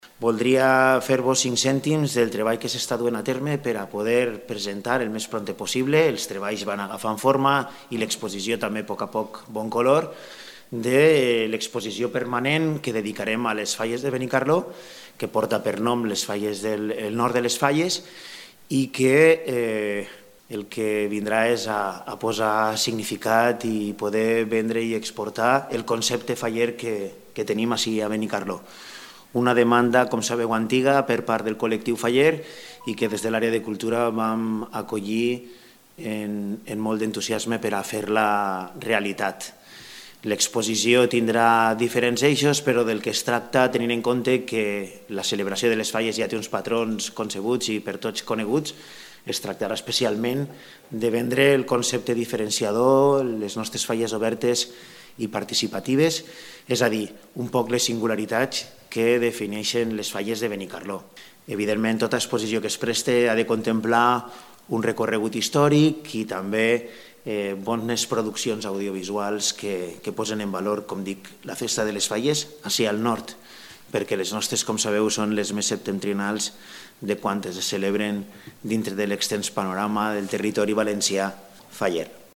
Pedro Manchón, regidor de Cultura.